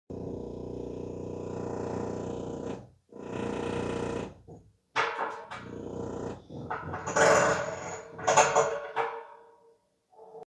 修理の音 10秒 無料ダウンロードとオンライン視聴はvoicebot.suで